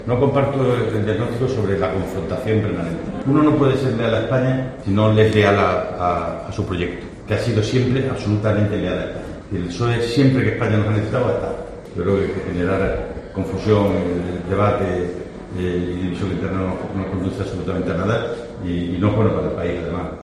"Yo creo que generar confusión, debate y división interna no conduce absolutamente a nada y no es bueno para el país además", ha señalado a continuación el presidente extremeño, preguntado este martes en Mérida -- al hilo de unas jornadas de mujeres dirigentes organizada por UGT-- por la defensa realizada en una entrevista en 'El Mundo' por García Page del líder del PP, Alberto Núñez Feijóo.